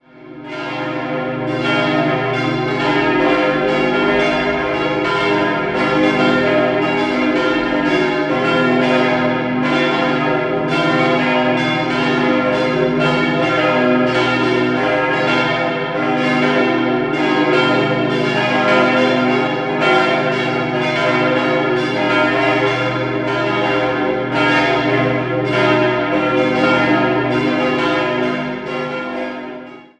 Anfang des 20. Jahrhunderts entstand die heutige Kirche, welche bereits einige Vorgängerbauten besaß. 5-stimmiges Geläut: c'-es'-f'-as'-c'' Die Glocken wurden 1911 von der Gießerei Rüetschi in Aarau gegossen.